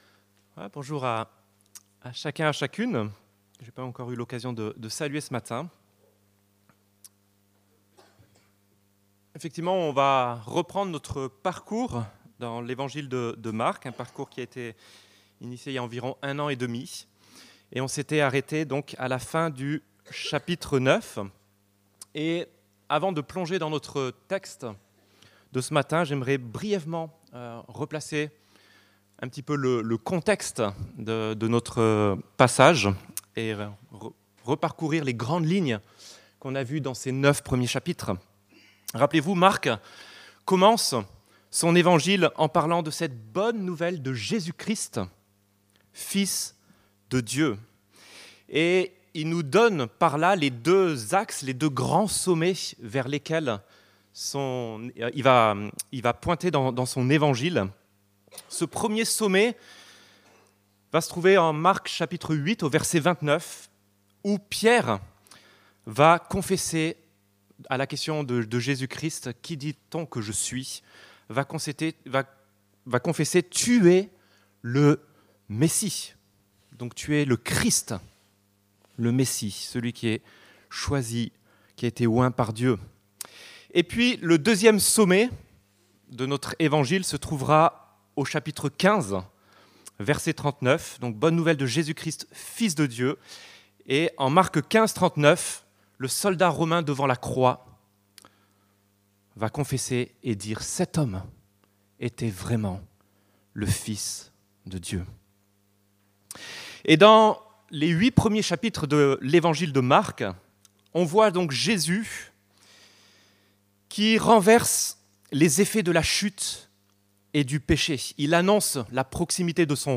Marc Prédication textuelle